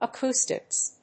音節a・cous・tics 発音記号・読み方
/əkúːstɪks(米国英語), ʌˈku:stɪks(英国英語)/